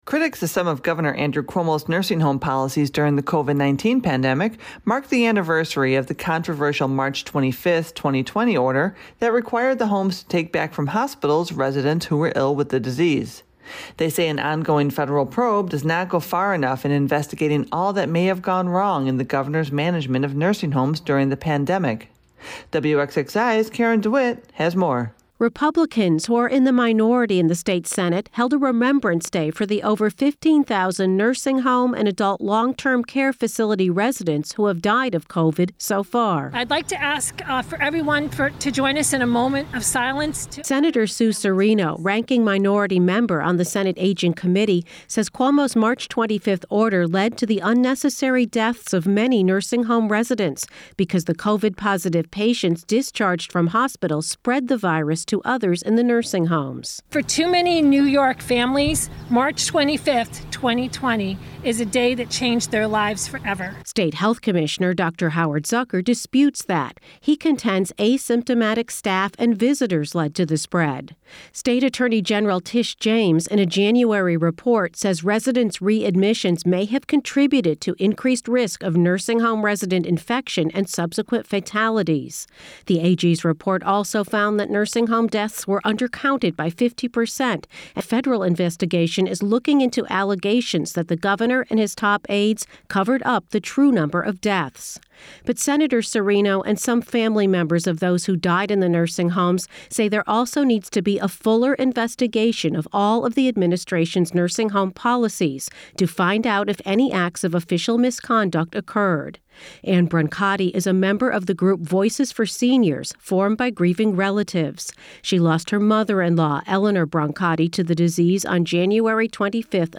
State lawmakers and relatives of New Yorkers who died of COVID-19 in nursing homes during the pandemic held a Day of Remembrance outside the State Capitol.